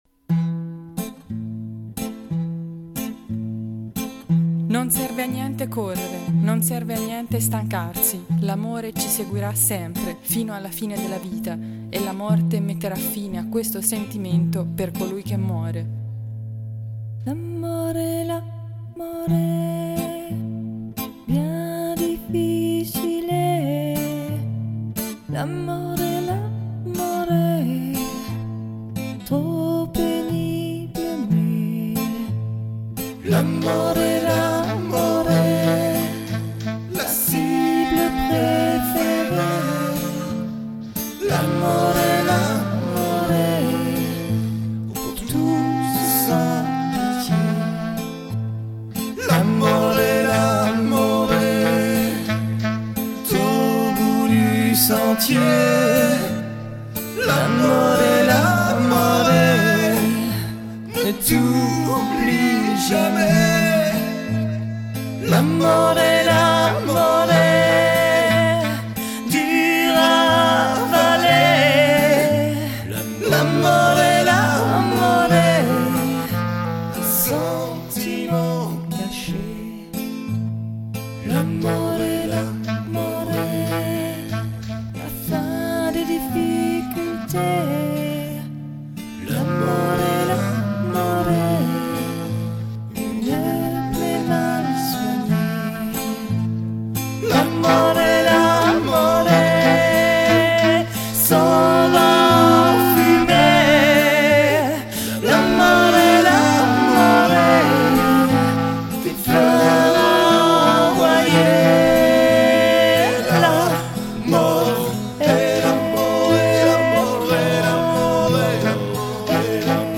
acoustik dynamiiik
chanson française décalée 2004